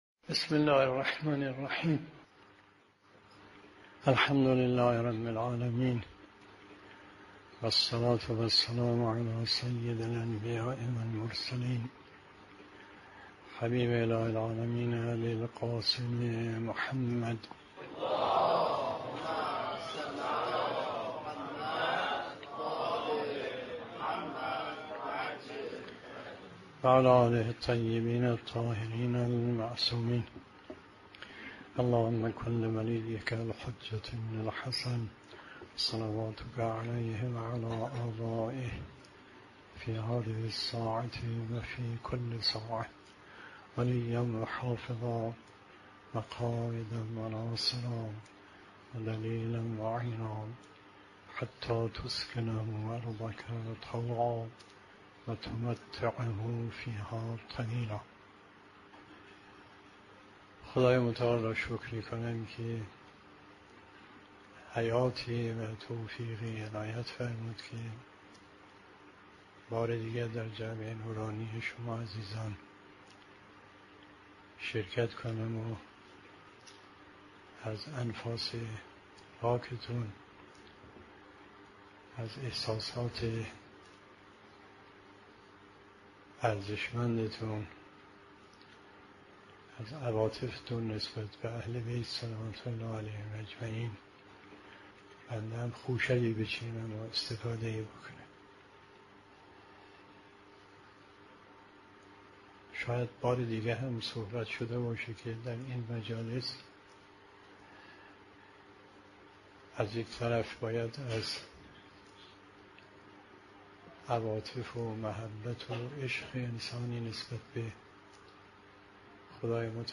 سخنرانی آیت الله مصباح یزدی درباره آفات علم آموزی